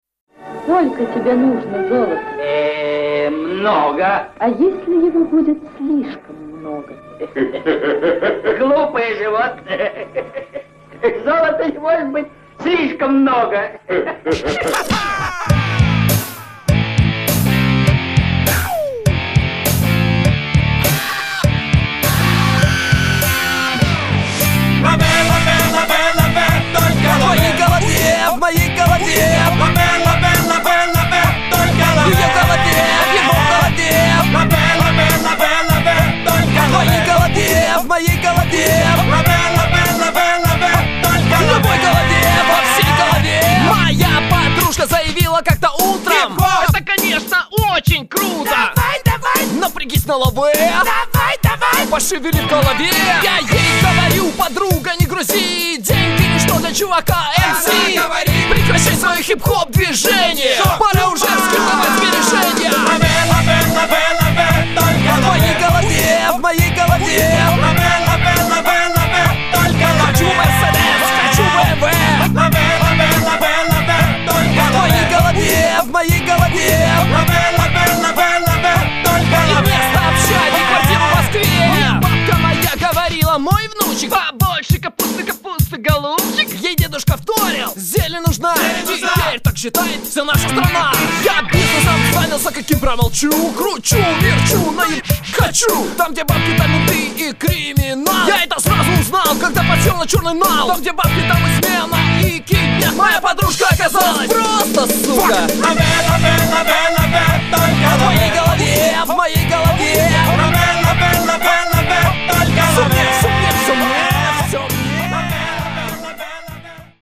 Стиль: PopChill Темп: 100 bpm
попсовая вещщичка на трёх с половиной аккродах и пяти нотах в мелодии